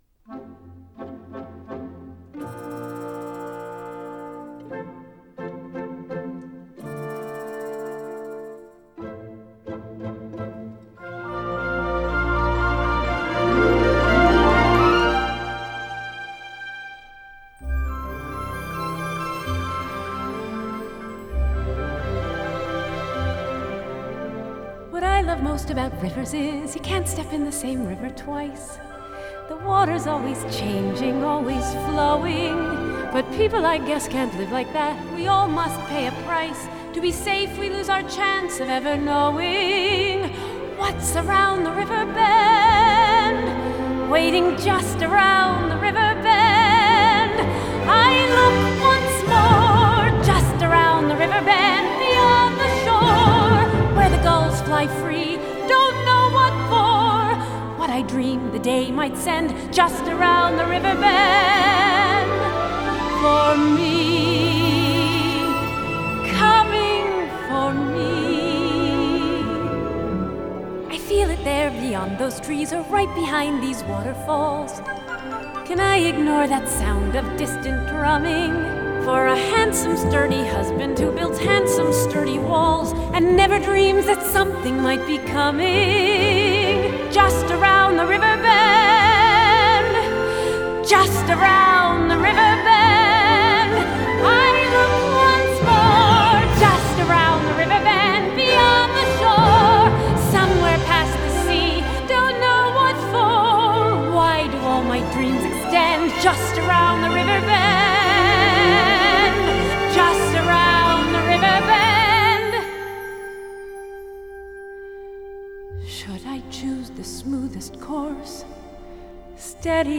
На этой странице вы найдете саундтрек к мультфильму \